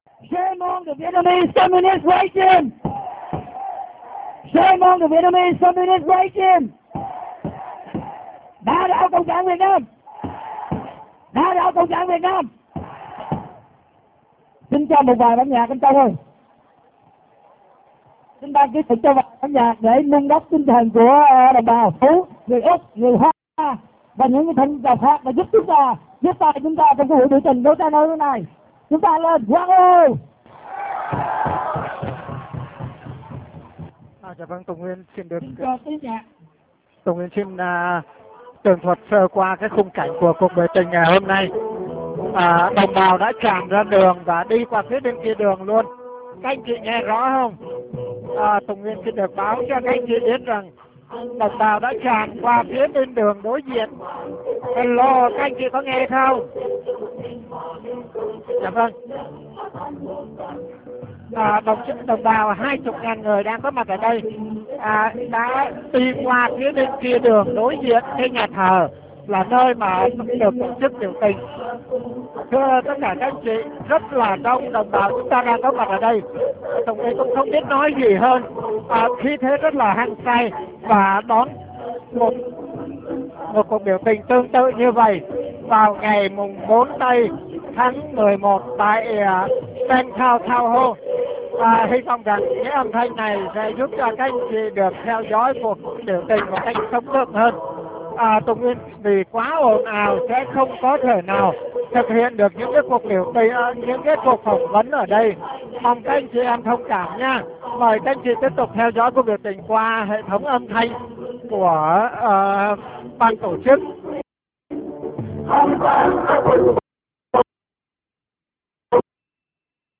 Mời qu� vị nghe một đoạn �m thanh trực tiếp truyền qua paltalk.